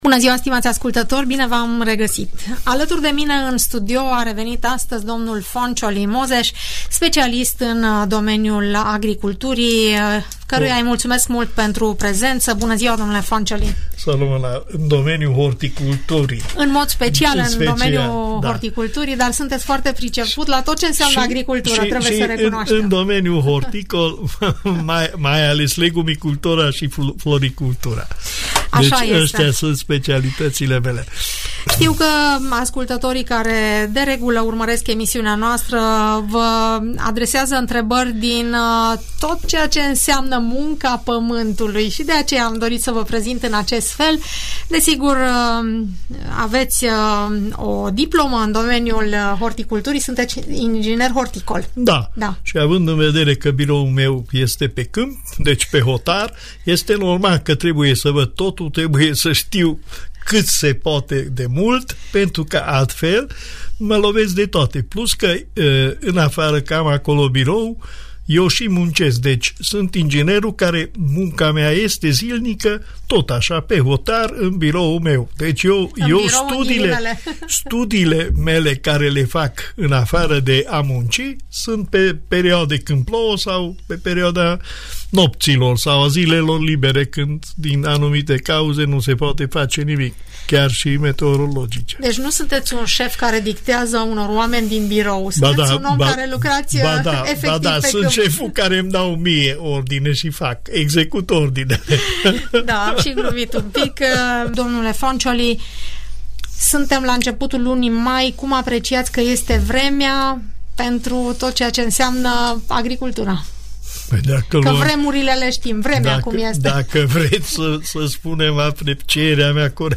Pentru ca fructele, legumele sau zarzavaturile să fie sănătoase, atenția și îngrijirea noastră trebuie să fie maximă în această perioadă. Inginerul horticol